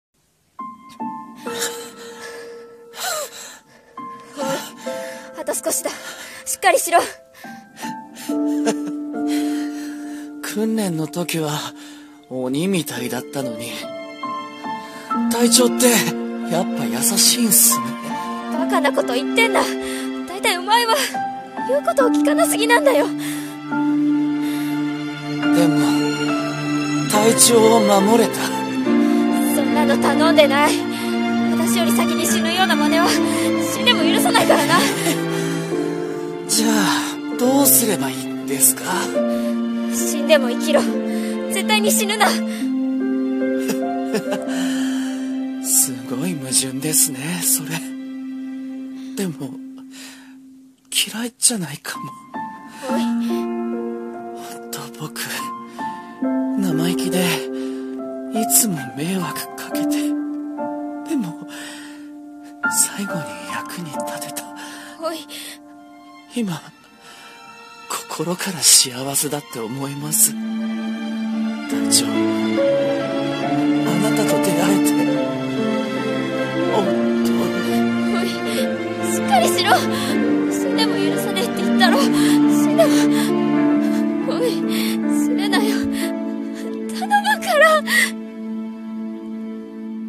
【声劇台本】 生意気な部下【掛け合い】